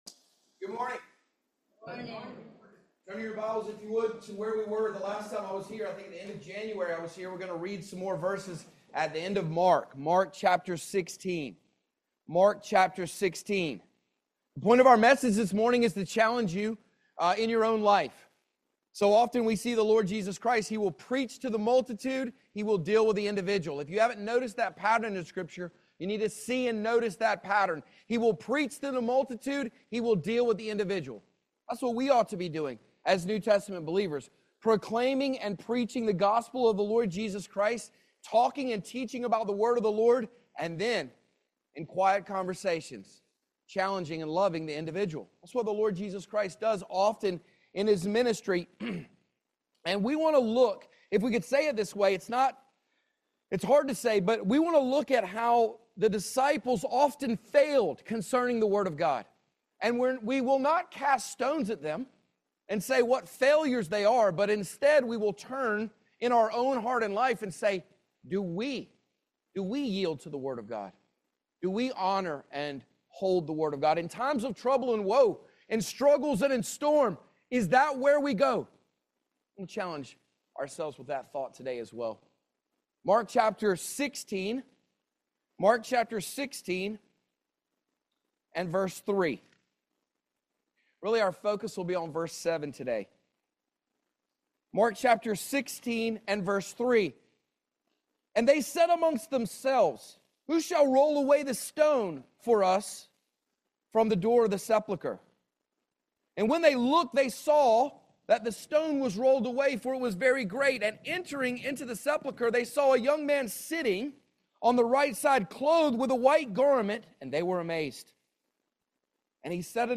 16:3-7 Service Type: Family Bible Hour The Word of God can change the believer’s perception of trials.